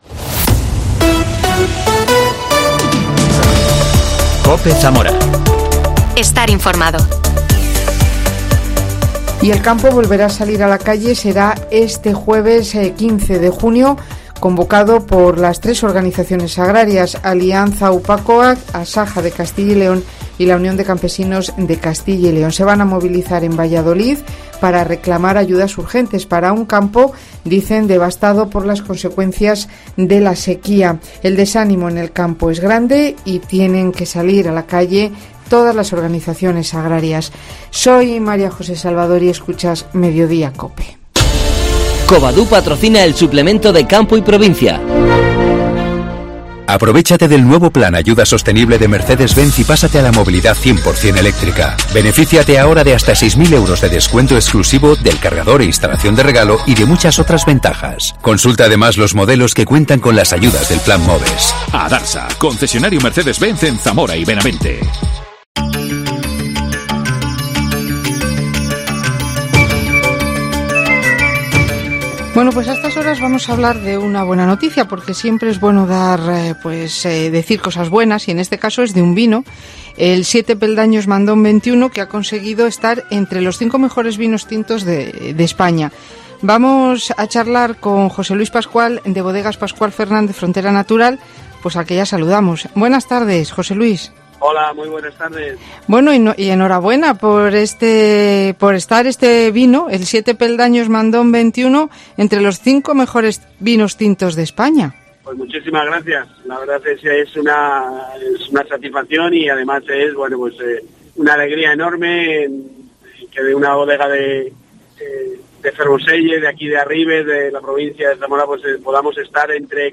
Entrevista al bodeguero